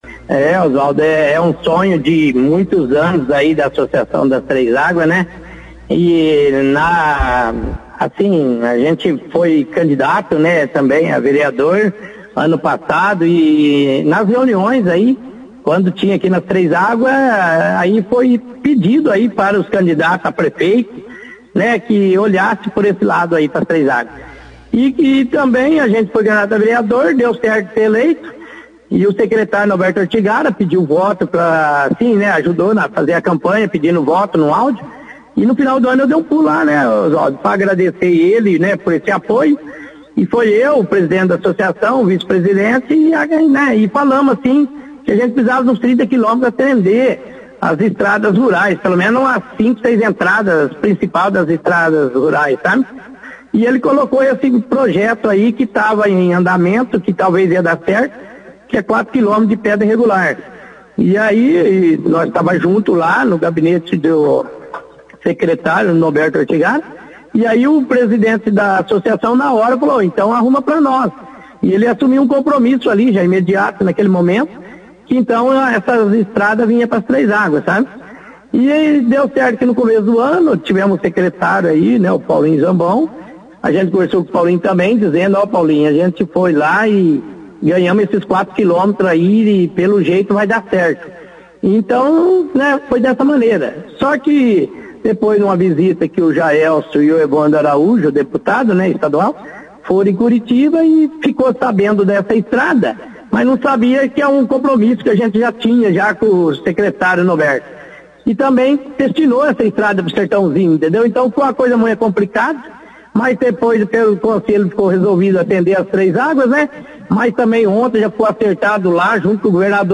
Vereador Lei fala da liberação de recurso para pavimentação com pedra irregular da estrada do bairro 3 Águas
O vereador Wanderley Aparecido da Silva, (Lei), (foto), participou da 2ª edição do jornal Operação Cidade desta quarta-feira, 10/11, falando como foi estar presente, ao lado prefeito de Bandeirantes, Jaelson Ramalho Matta, na última terça-feira, 09/11, em Curitiba, junto ao governador Ratinho Junior, para a assinatura de um convênio entre o município e o governo do Paraná, através do programa Estradas da Integração, no valor de R$ 1,15 milhão para pavimentação com pedra irregular de cerca de 4 quilômetros da Estrada do Bairro 3 Águas.